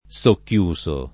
vai all'elenco alfabetico delle voci ingrandisci il carattere 100% rimpicciolisci il carattere stampa invia tramite posta elettronica codividi su Facebook socchiuso [ S okk L2S o ] part. pass. di socchiudere e agg.